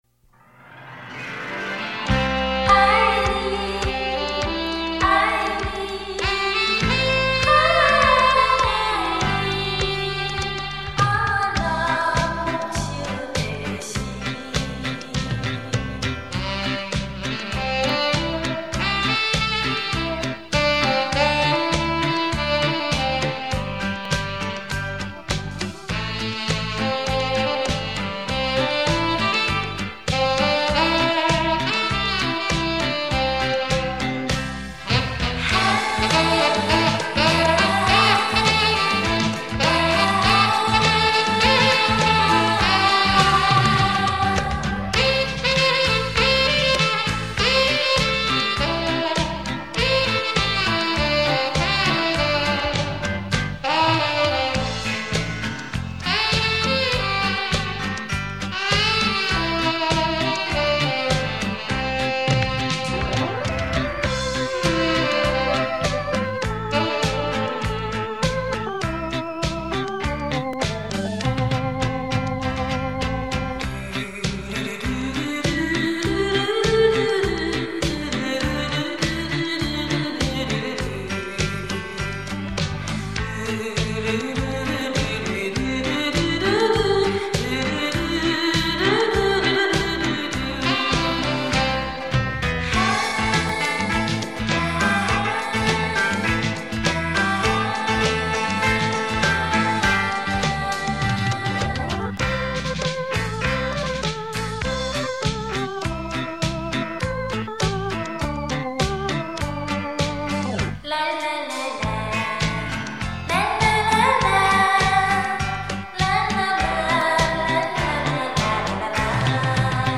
享受柔和优美的演奏 值得您细细品味收藏